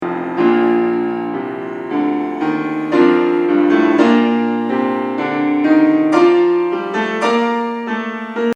First comes a kind of victorious feeling, going up:
op2no2-2victorious.mp3